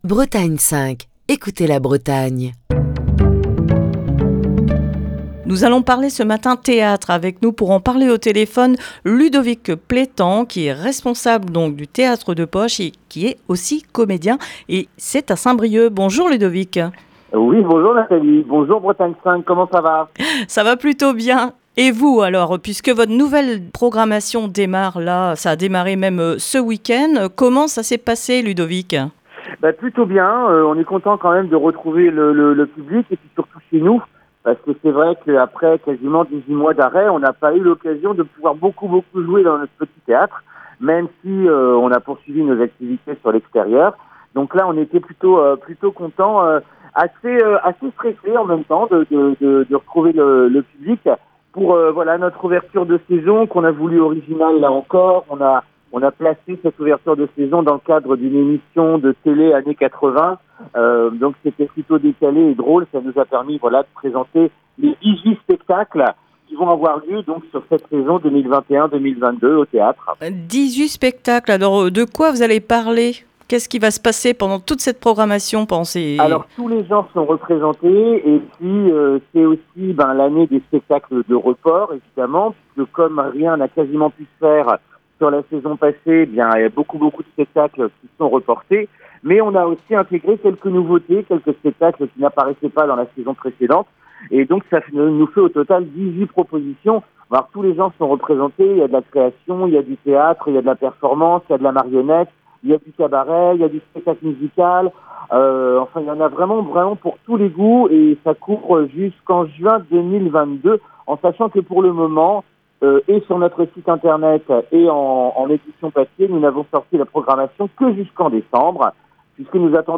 Émission du 13 octobre 2021. Ce mercredi dans le Coup de fil du matin